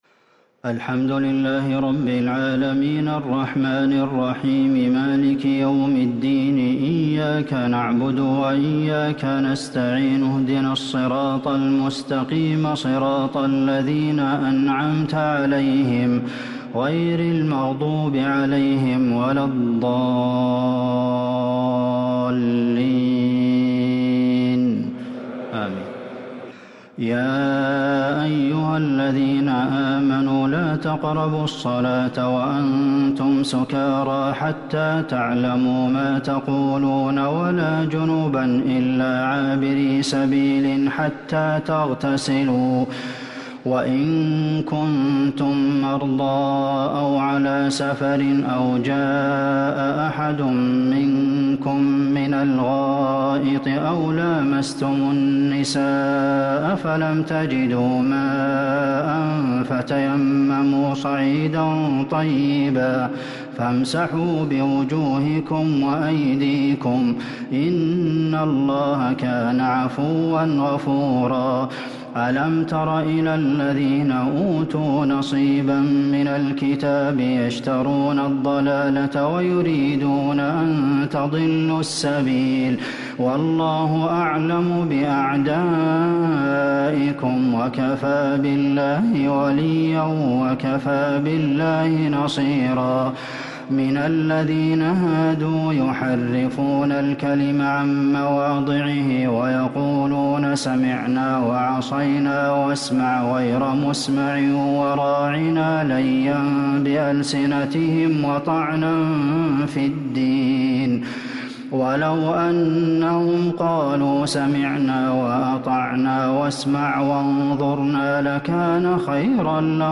تراويح ليلة 6 رمضان 1444هـ من سورة النساء {43-87} Taraweeh 6 st night Ramadan 1444H Surah An-Nisaa > تراويح الحرم النبوي عام 1444 🕌 > التراويح - تلاوات الحرمين